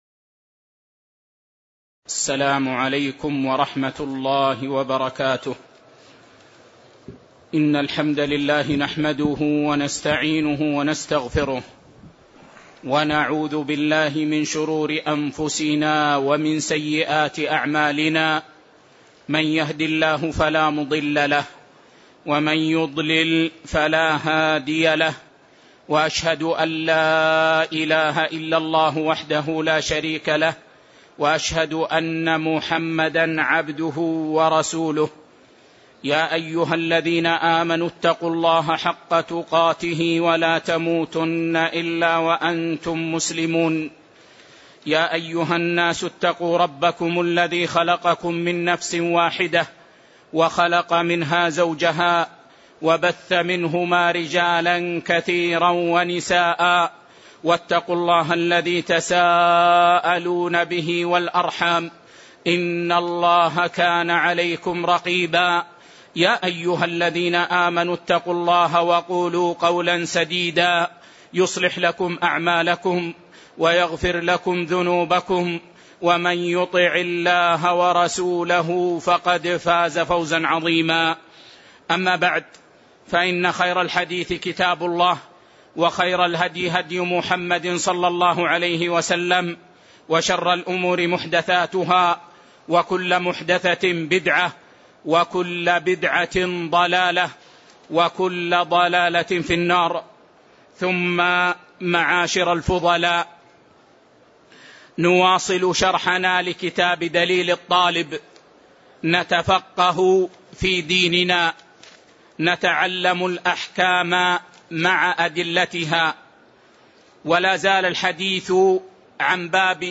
تاريخ النشر ٢٥ ربيع الثاني ١٤٣٧ هـ المكان: المسجد النبوي الشيخ